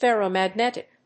音節fèrro・magnétic 発音記号・読み方
/ˌfɛroˌmæˈgnɛtɪk(米国英語), ˌferəʊˌmæˈgnetɪk(英国英語)/
アクセント・音節fèrro・magnétic